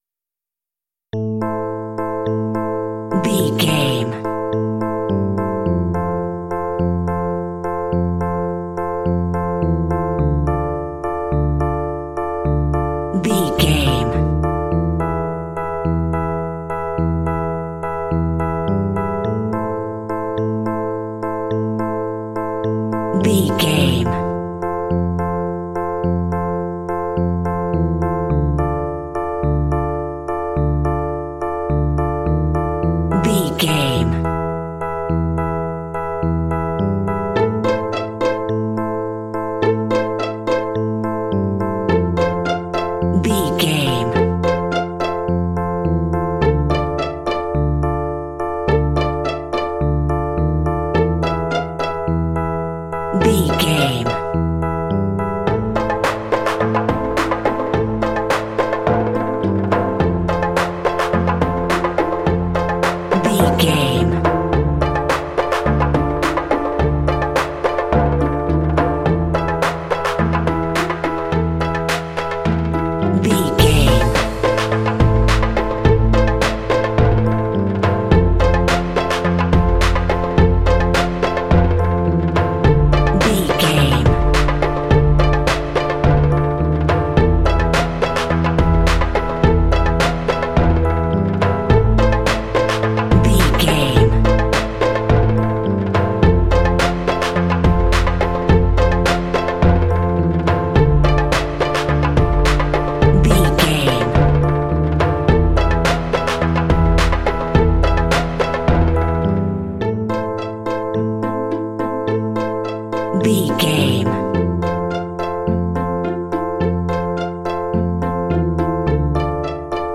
Aeolian/Minor
scary
ominous
dark
eerie
electric piano
strings
percussion
drums
creepy
horror music